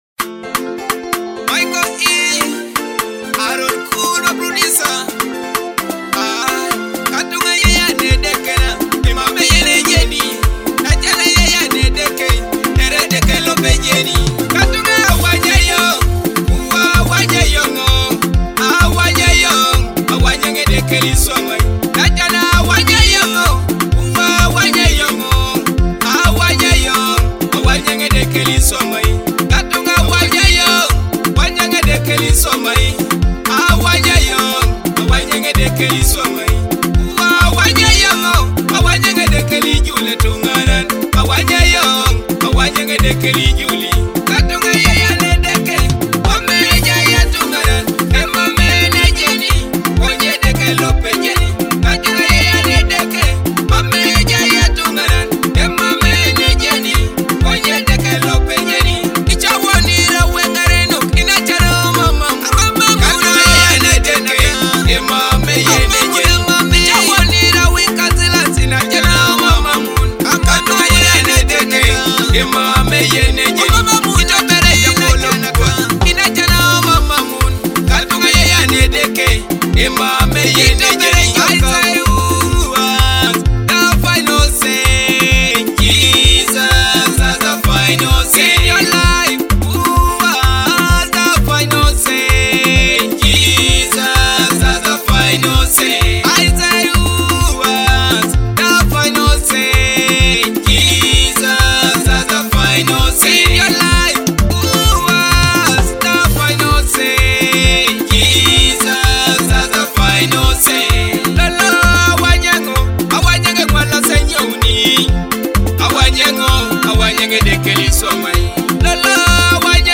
and trending gospel music